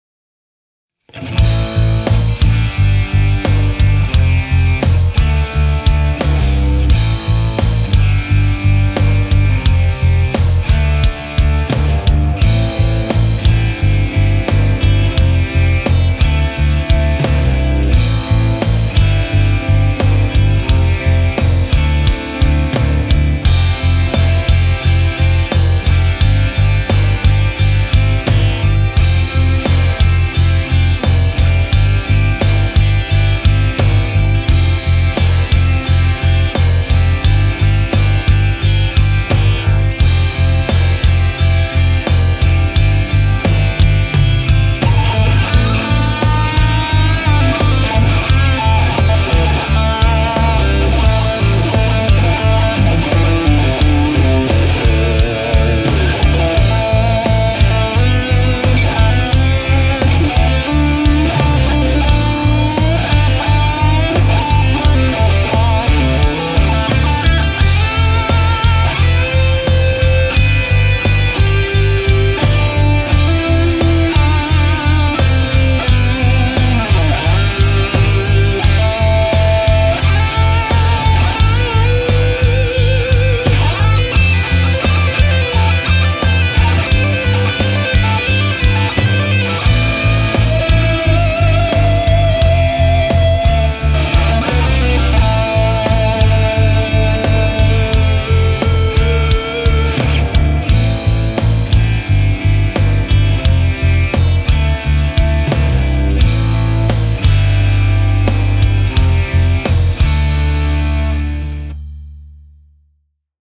Ist a bissl snotty gespielt ich weiß – aber den Sound finde ich so geil. War ein Treble Booster HW -> Interface -> das Marshall Plugin mit etwas Delay und Reverb, sonst nichts.